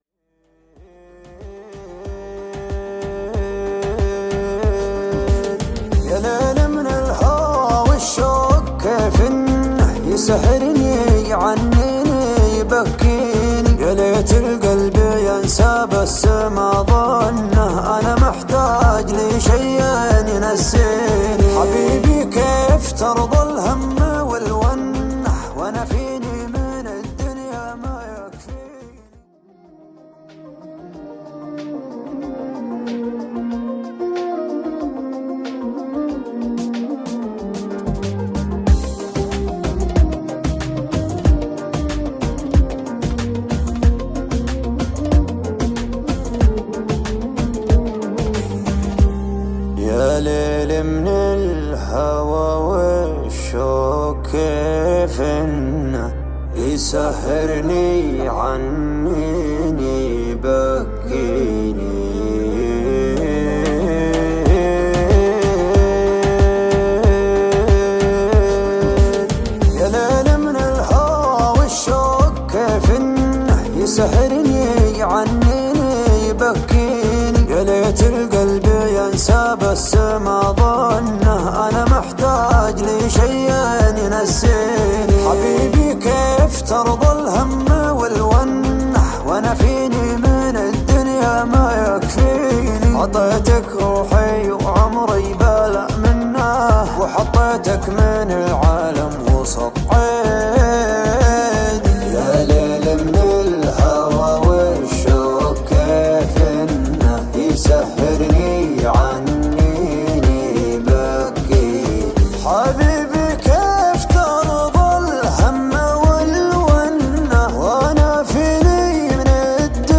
شيلة خليجية
الشيلات